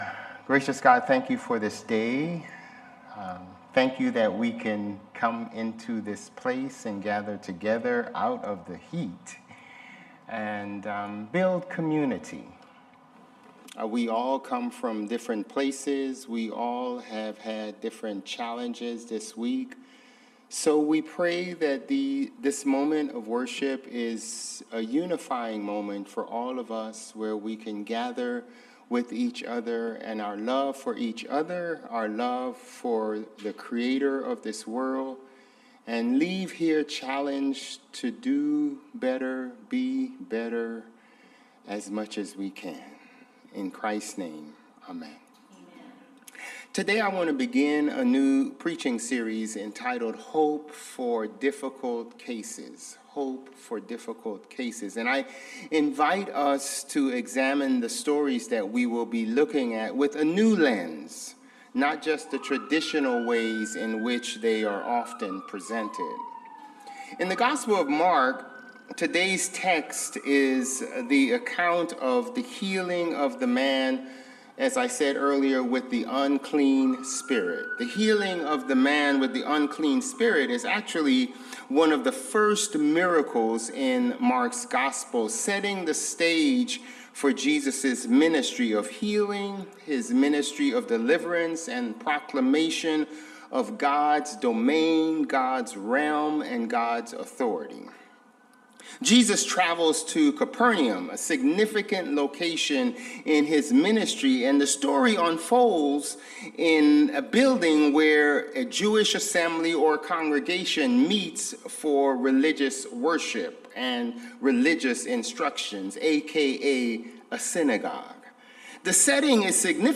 Sermons | Bethel Lutheran Church
August 10 Worship